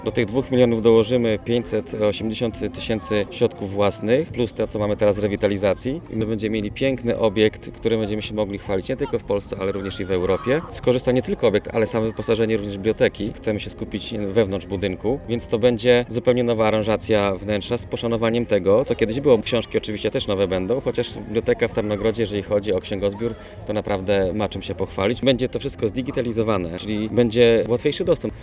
– Otrzymaliśmy maksymalną kwotę o jaką można się było ubiegać – 2 miliony złotych – mówi burmistrz Tarnogrodu, Paweł Dec.